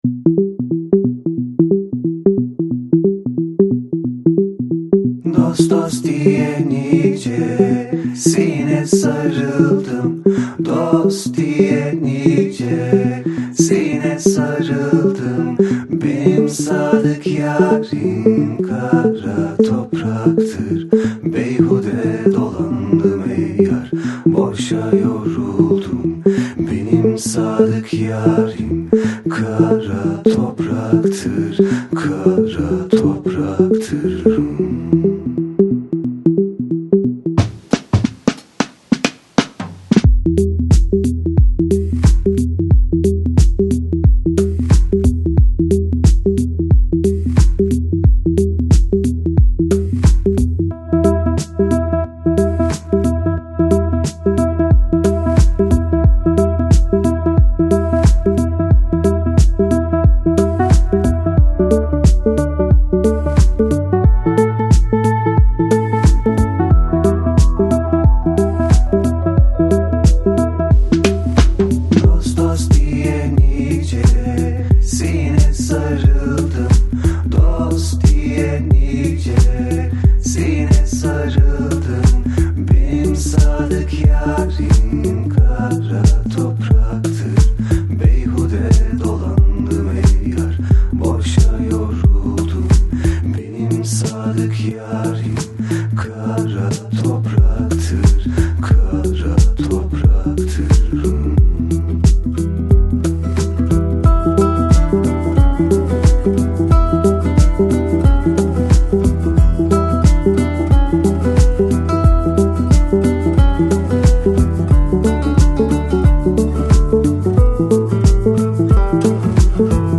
Жанр: Ambient | Balearic